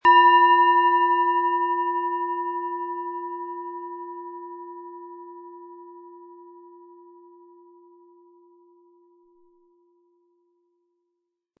Planetenschale® Begeistert sein können & Lichtvoll sein mit Chiron, Ø 11,5 cm, 180-260 Gramm inkl. Klöppel
Planetenton 1
Unter dem Artikel-Bild finden Sie den Original-Klang dieser Schale im Audio-Player - Jetzt reinhören.
Durch die überlieferte Fertigung hat sie dafür diesen besonderen Spirit und eine Klangschwingung, die unser Innerstes berührt.
SchalenformBihar
MaterialBronze